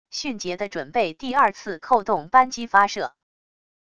迅捷的准备第二次扣动扳机发射wav下载